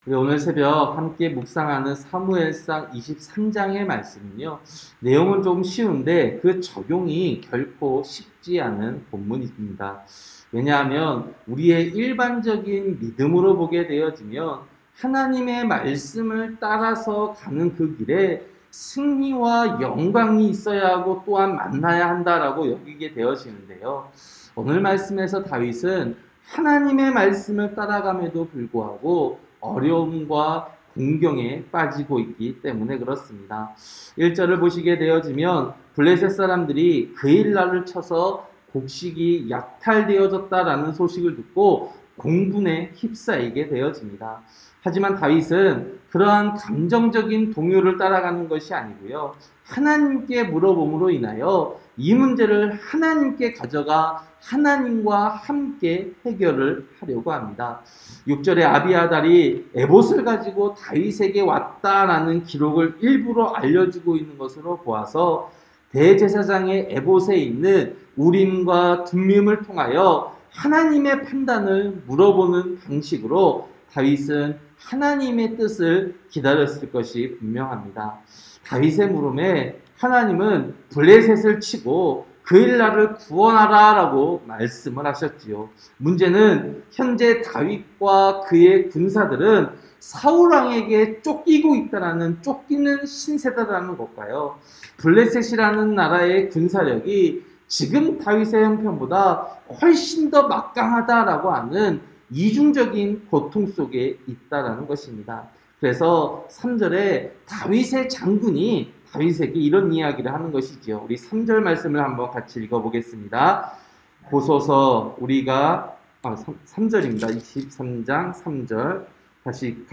새벽설교-사무엘상 23장